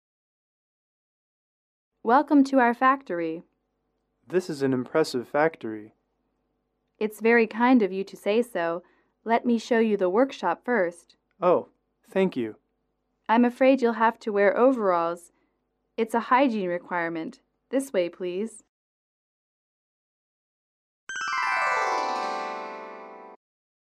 英语口语情景短对话57-2：参观工厂(MP3)